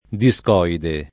[ di S k 0 ide ]